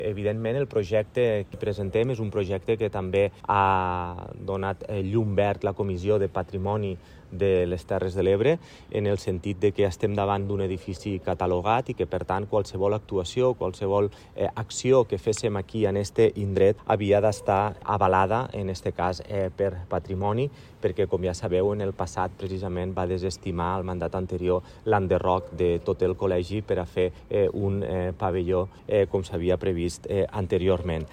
L’alcalde Tortosa Jordi Jordan, ens ho explica